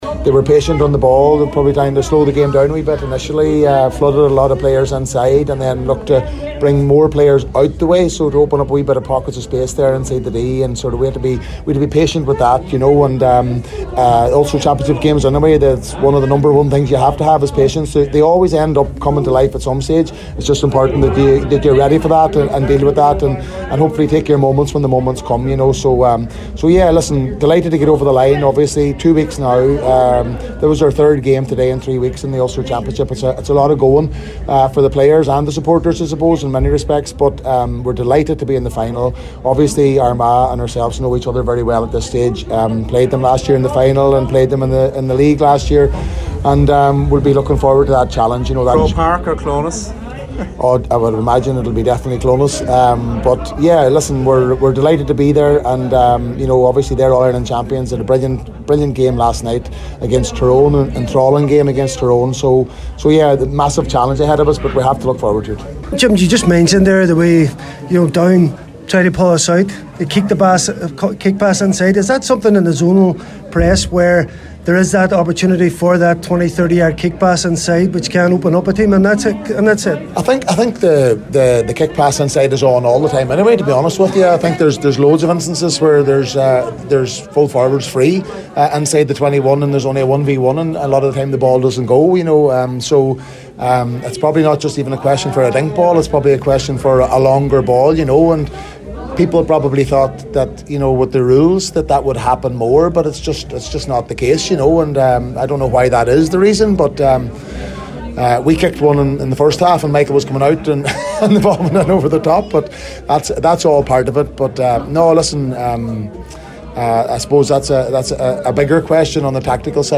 Donegal manager Jim McGuinness